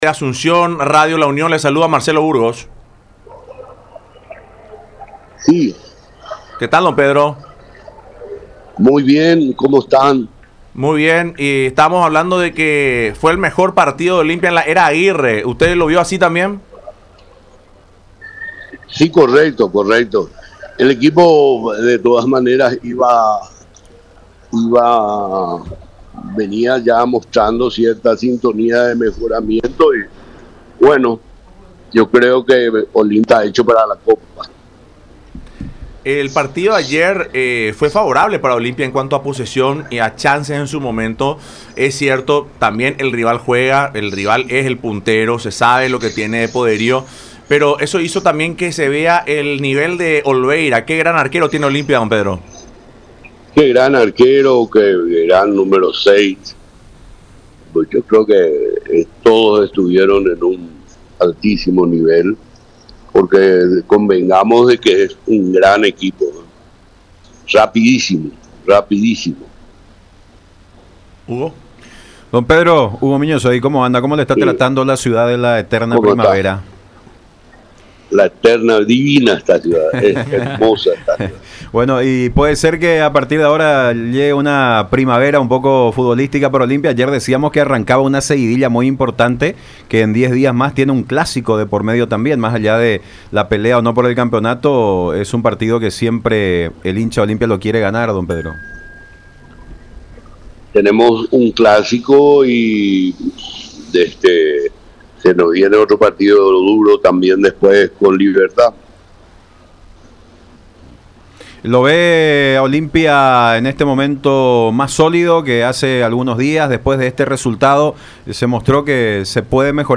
en contacto con Fútbol Club a través de Radio La Unión y Unión TV.